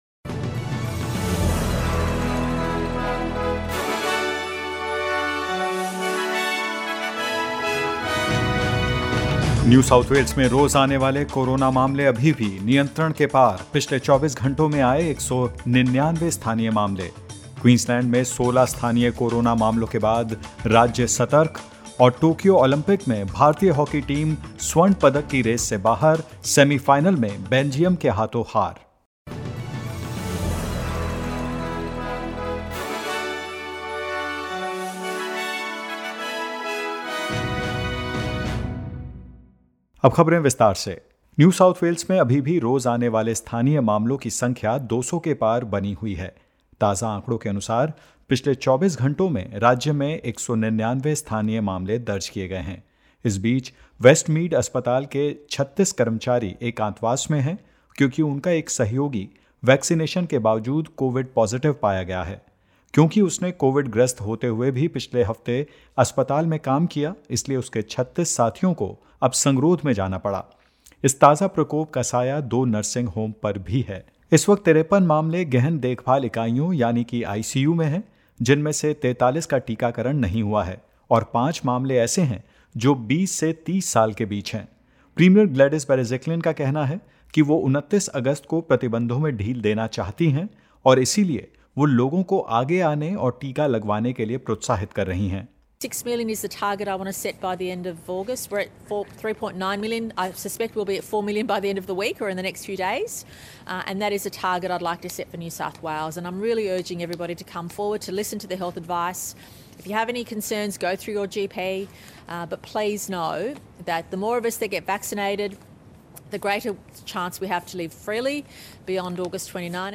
In this latest SBS Hindi News bulletin of Australia and India: Queensland's active cases climb to 47; Victoria records four new locally acquired cases, all linked to existing outbreaks and were in quarantine while infectious and more.